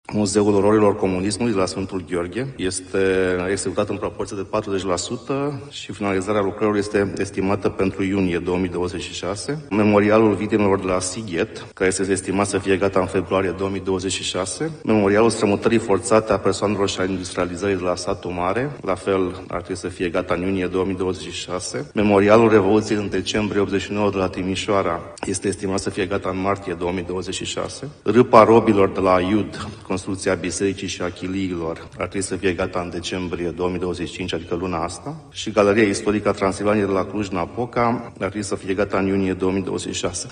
Senatorul Cristian Ghinea, fost ministru al Fondurilor Europene, a precizat care vor fi acele muzee și când ar urma să fie ele finalizate